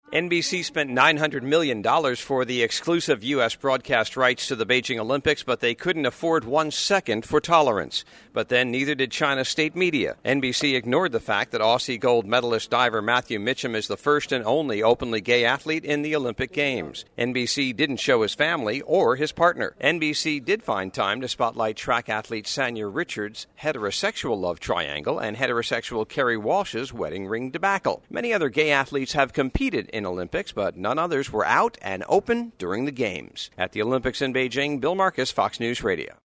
2008 Beijing Olympics, China, Fox News Radio, Selected Reports: